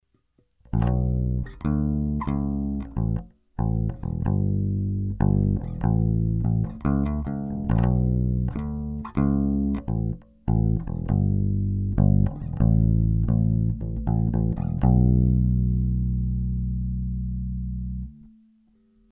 Треки были записаны напрямую в линейный вход звуковой карточки без какой либо текущей и последующей обработки звука.
New bass without cut
Вышеприведенные треки доработанного баса с онборд преампом. 1-й трек записан - при вырезанных средних частотах (т.е. согласно пиведенной в начале схеме при замкнутом переключателе S1); 2-й трек - без выреза частот (S1 разомкнут соот.)
new_bass_without_cut.mp3